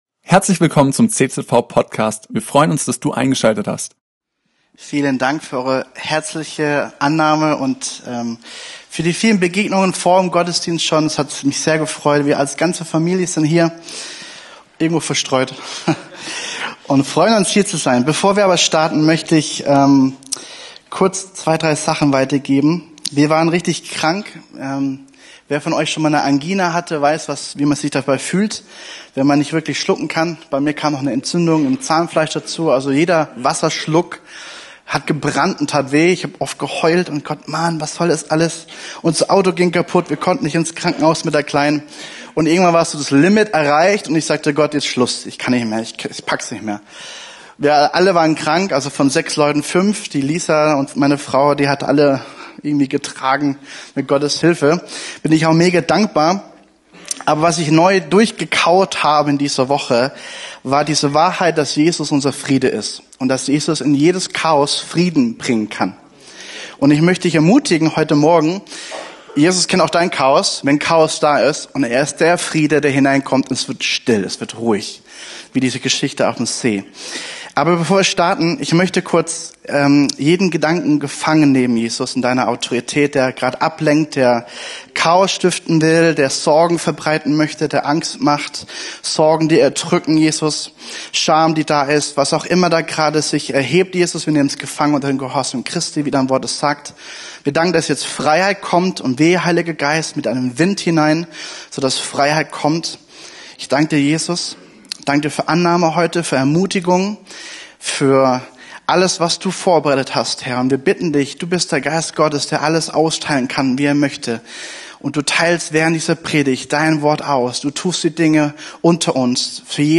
Predigtserie